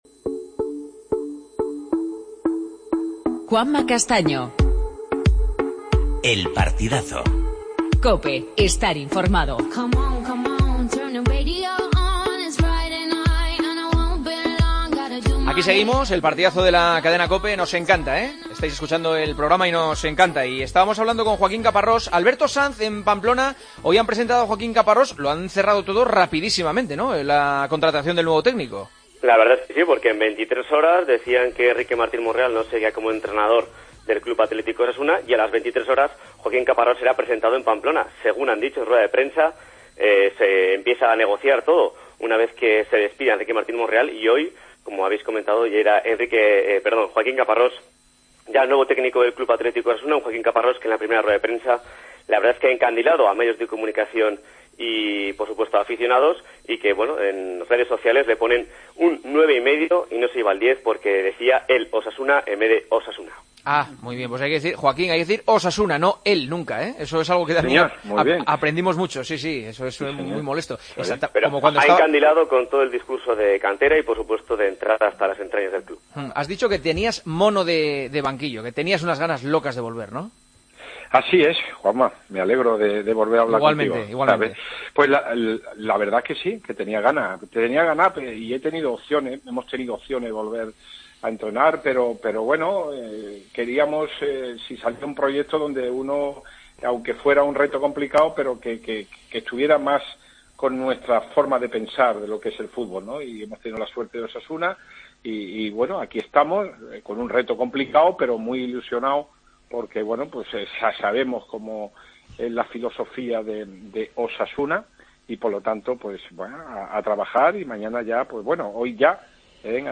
AUDIO: Entrevista a Joaquín Caparrós, nuevo entrenador de Osasuna.